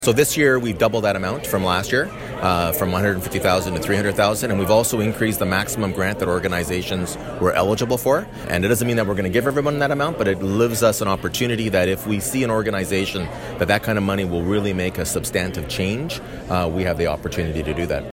Belleville Mayor Mitch Panciuk speaks at the OLG Recognition Event on March 21, 2019 at the Quinte Sports and Wellness Centre.